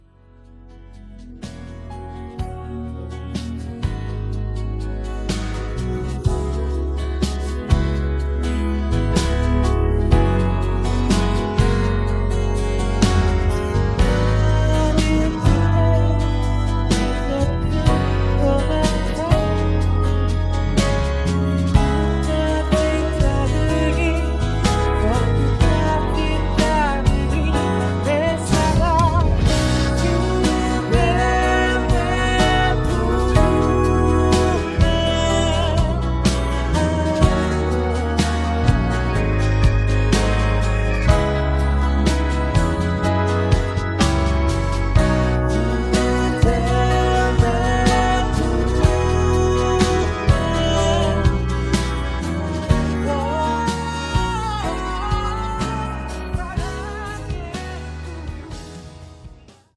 음정 -1키 6:19
장르 가요 구분 Voice MR
보이스 MR은 가이드 보컬이 포함되어 있어 유용합니다.